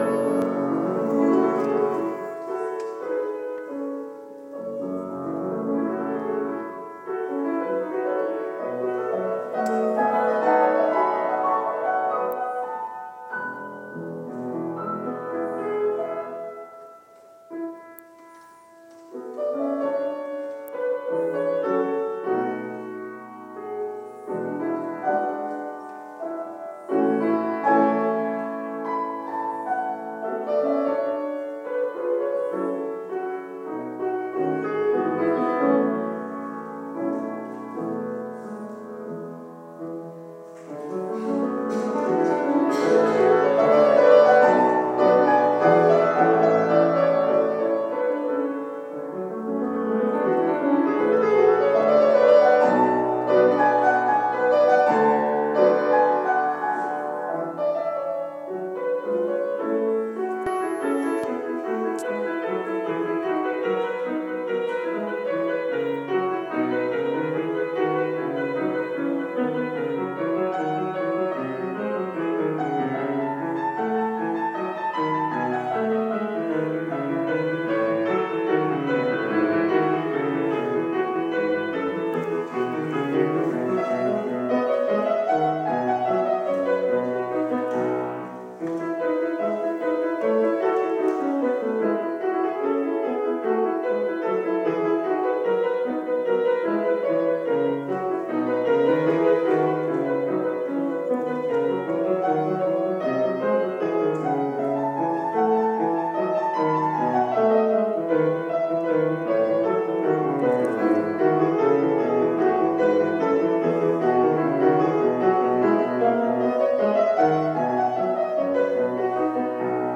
klavierabend 1